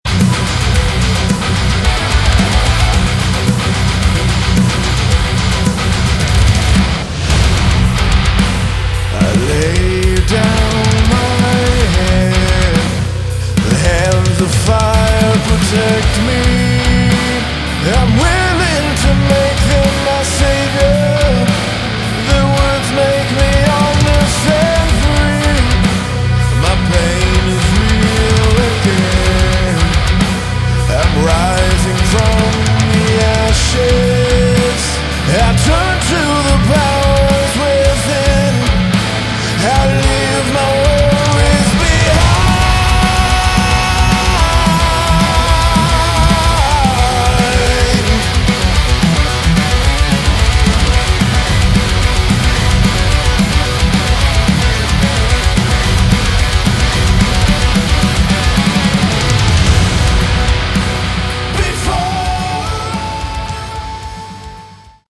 Category: Melodic Metal
vocals, guitars
bass
drums